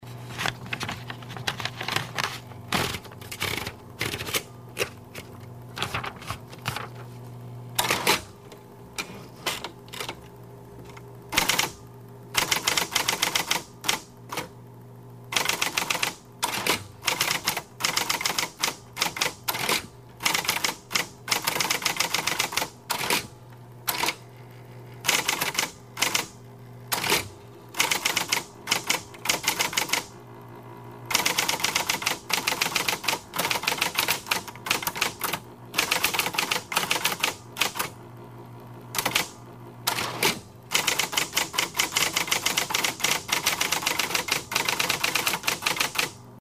Звуки программирования
Звук заправки бумаги и печать текста